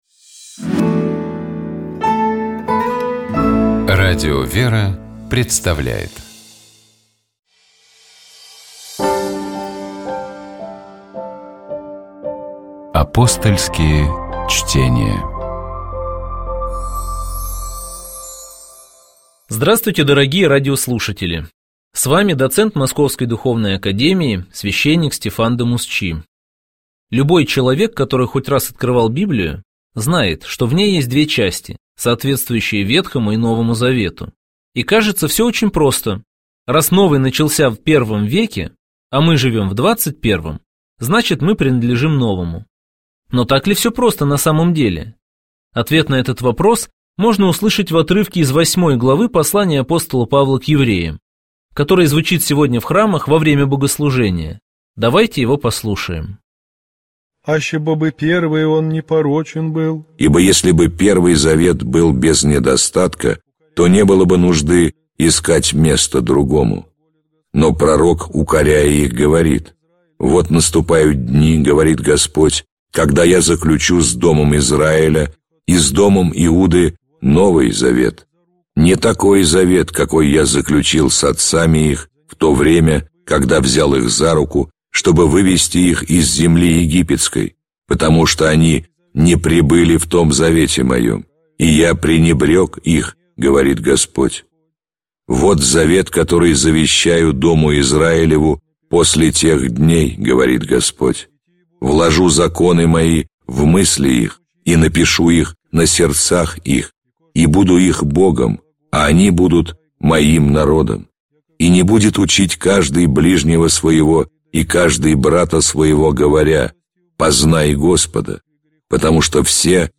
Комментирует священник